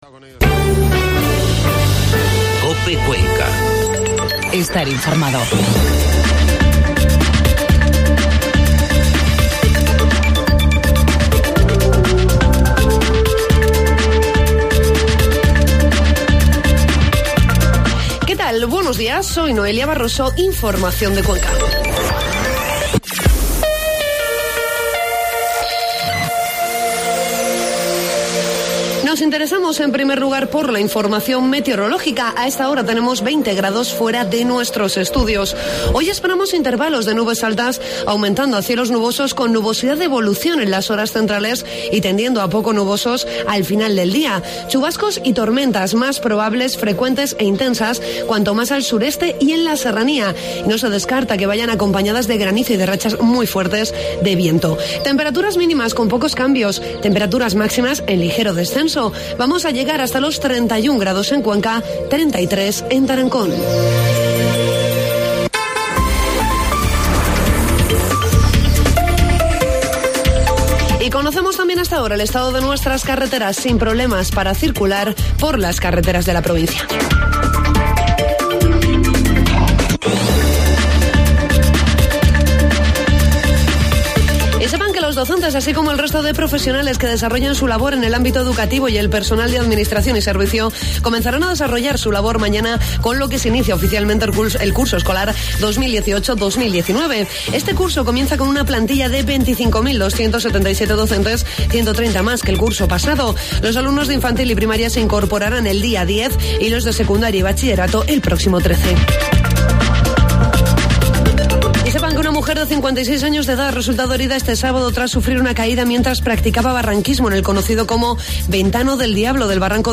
Informativo matinal 3 de septiembre